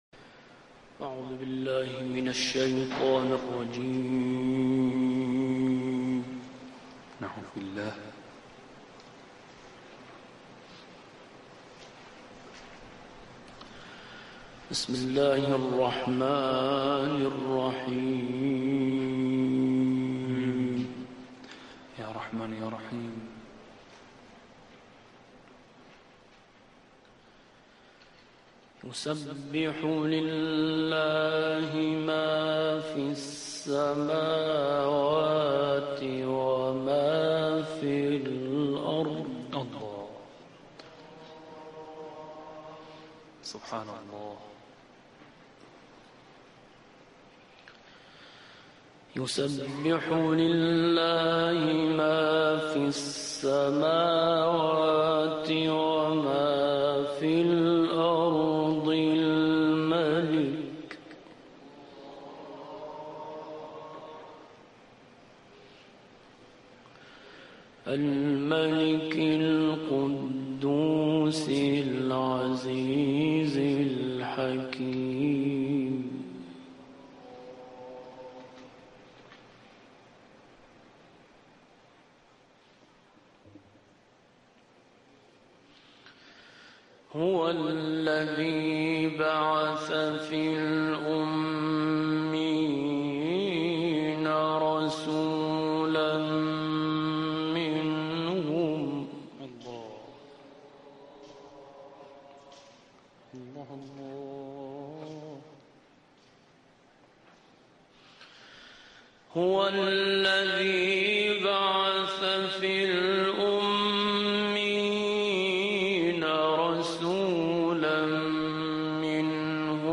مثال زیبای قرآن برای بی‌توجهی به قوانین الهی در سوره «جمعه»+ تلاوت